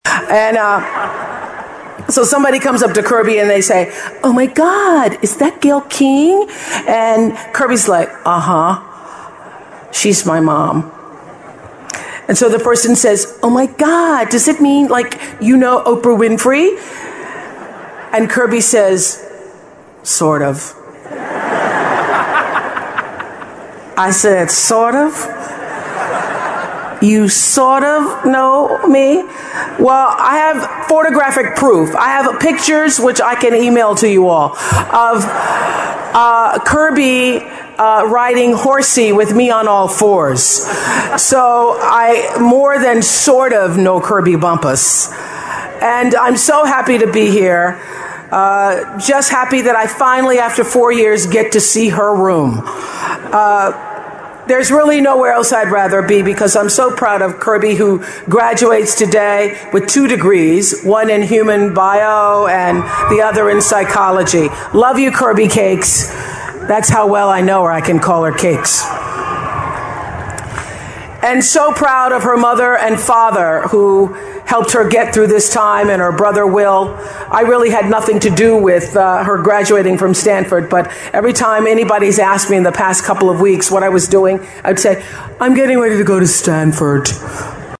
名人励志英语演讲 第140期:感觉失败及寻找幸福(2) 听力文件下载—在线英语听力室